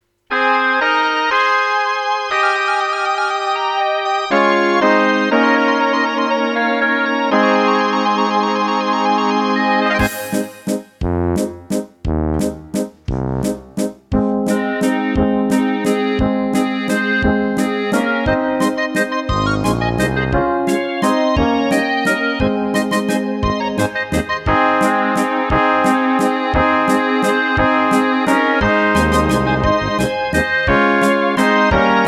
Rubrika: Národní, lidové, dechovka